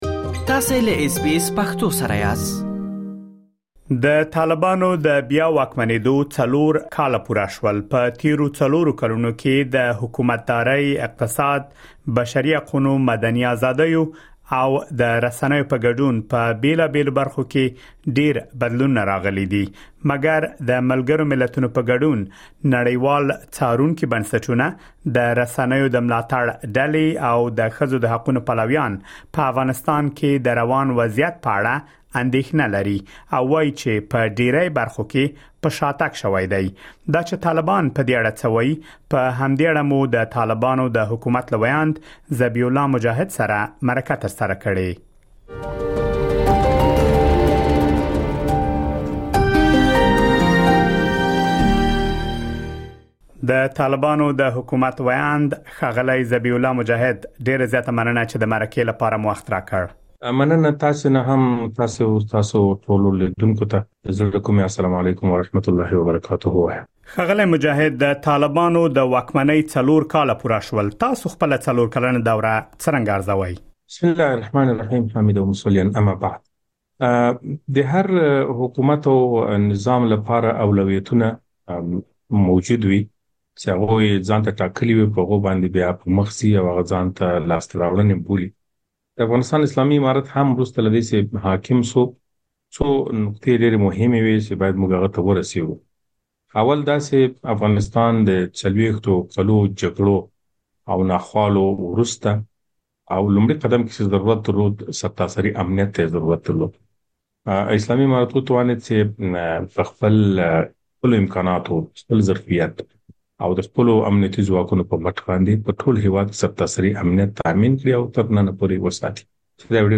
د طالبانو د حکومت له وياند ذبيح الله مجاهد سره ځانګړې مرکه